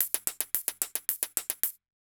Index of /musicradar/ultimate-hihat-samples/110bpm
UHH_ElectroHatA_110-05.wav